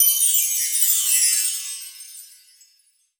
chimes_magical_bells_05.wav